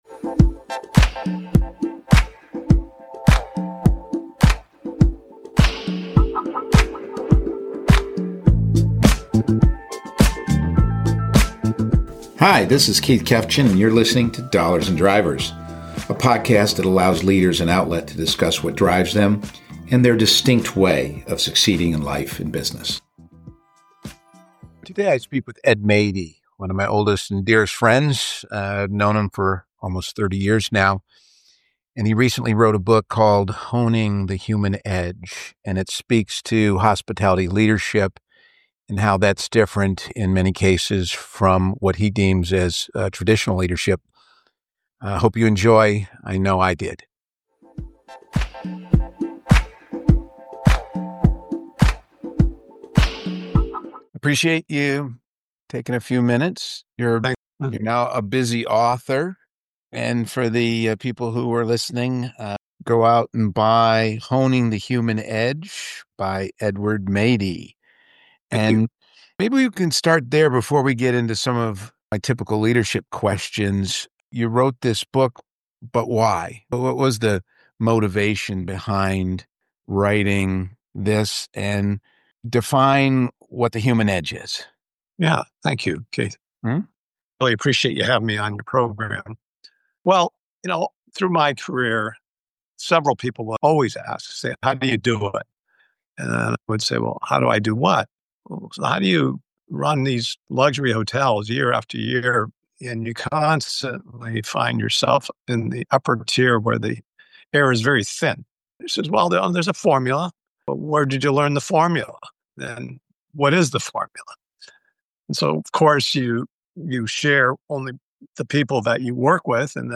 where he interviews business leaders regarding their "playbook" for success.